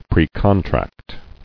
[pre·con·tract]